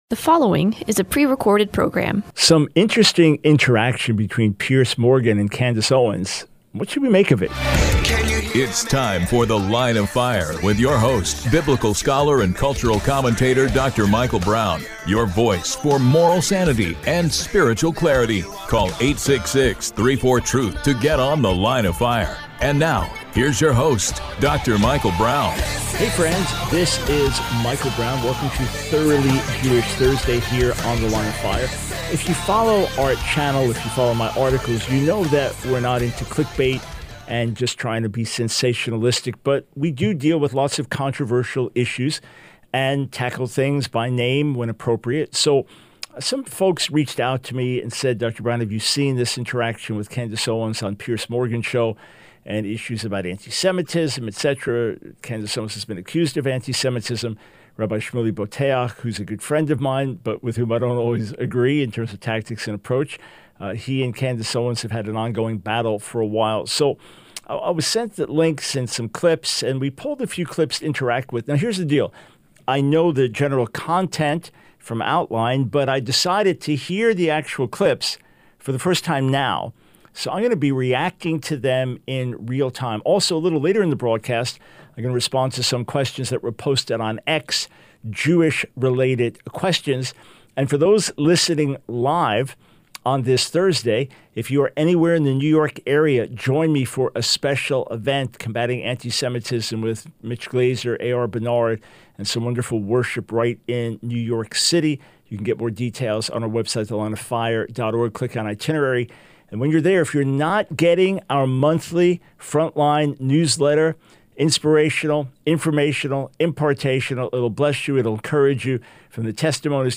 The Line of Fire Radio Broadcast for 06/20/24.